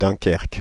Dunkirk (UK: /dʌnˈkɜːrk/ dun-KURK; US: /ˈdʌnkɜːrk/ DUN-kurk;[3][4] French: Dunkerque [dœ̃kɛʁk]
Fr-Dunkerque.ogg.mp3